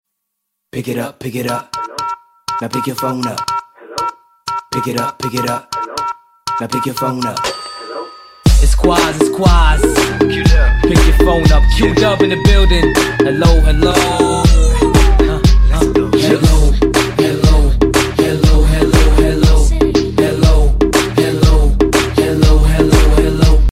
Comedy Ringtones